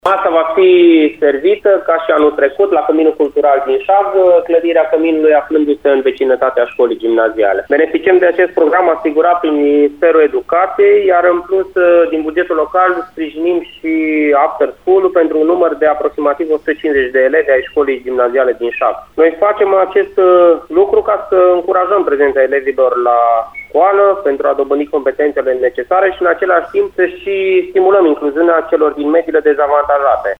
Preșcolarii și școlarii din localitate au primit, și anul trecut, acest suport alimentar prin programul național „O masă caldă”, spune primarul Flavius-Alin Roșu.